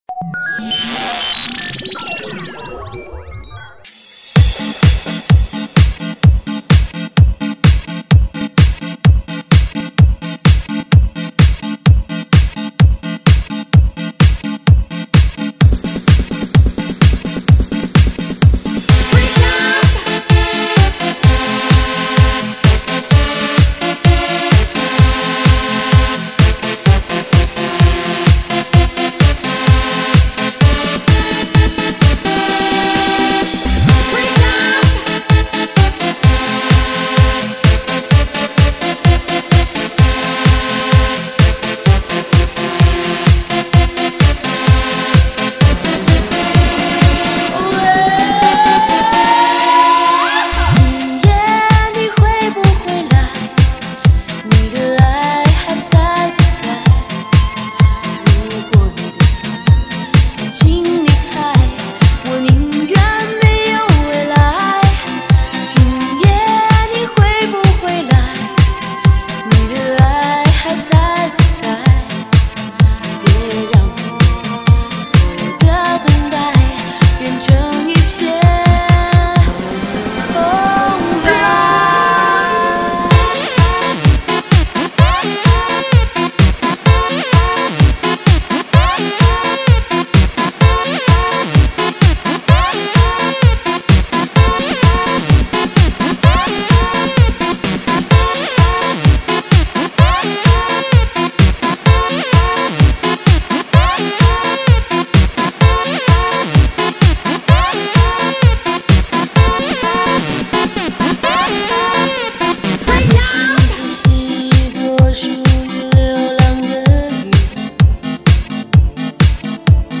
最受欢迎的慢摇舞曲，你忙里偷闲的首选音乐！